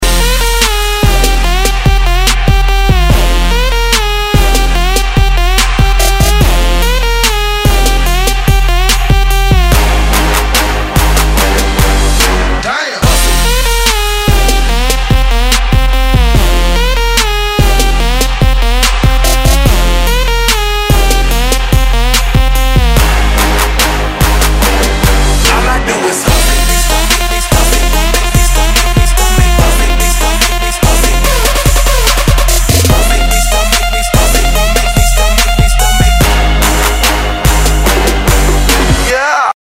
Dubstep рингтоны